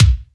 KICK FAT B00.wav